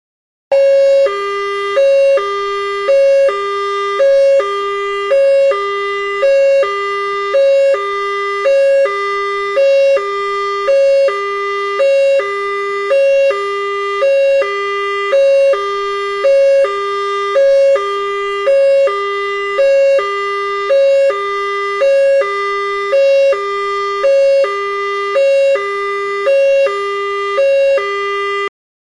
Звуки полицейской сирены